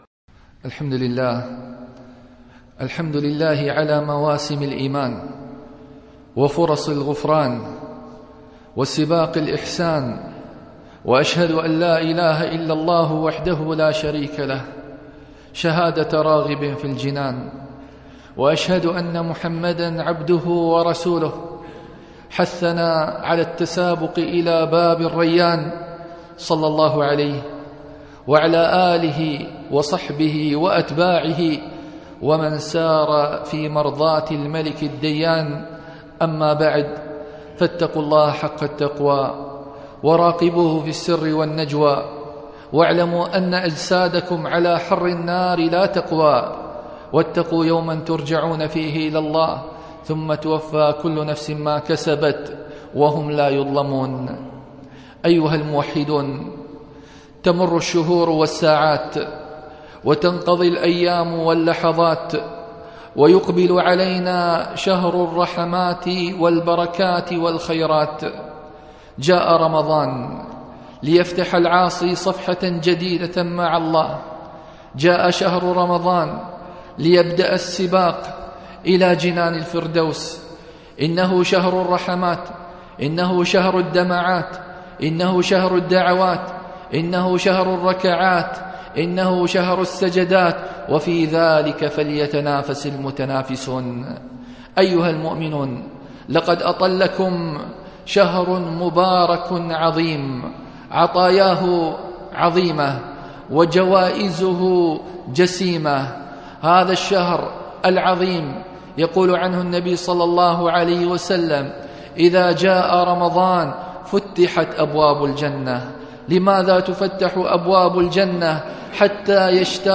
خطب - Page 2 of 923 - موقع دروس الإمارات